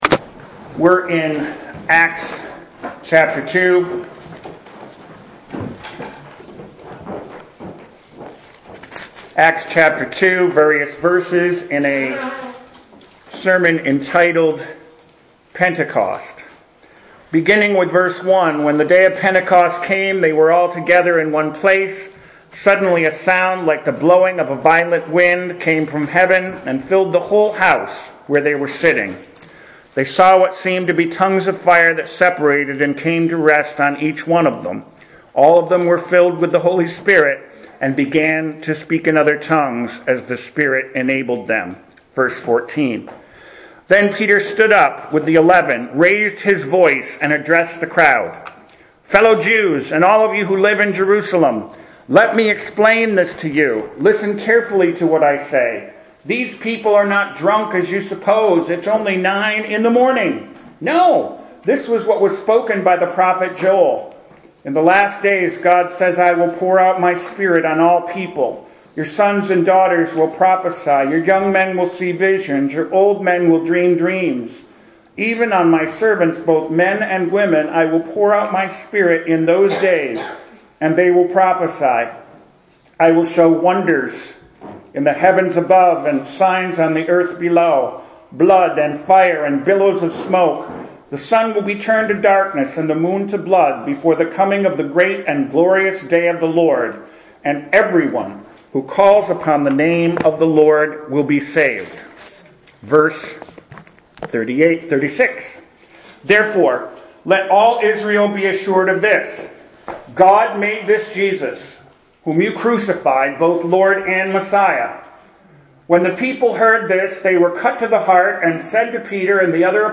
PENTICOST!! What a SERMON